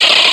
Grito de Shuppet.ogg
Grito_de_Shuppet.ogg